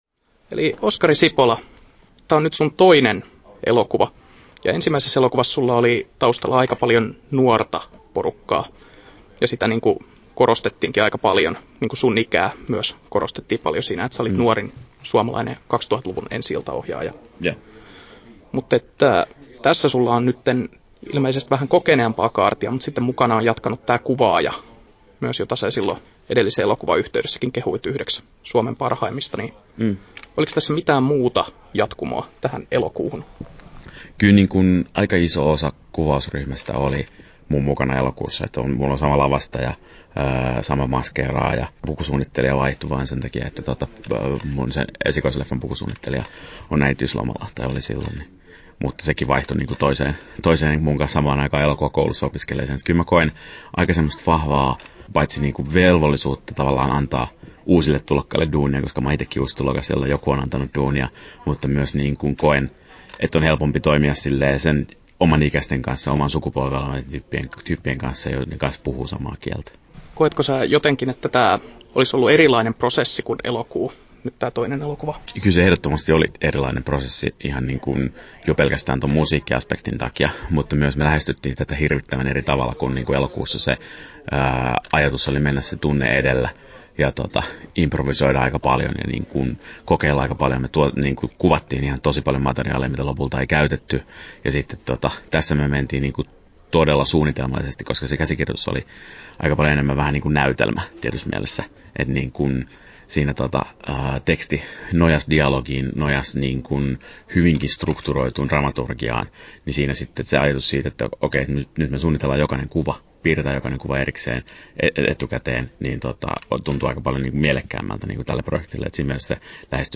Haastattelussa
11'57" Tallennettu: 4.8.2015, Turku Toimittaja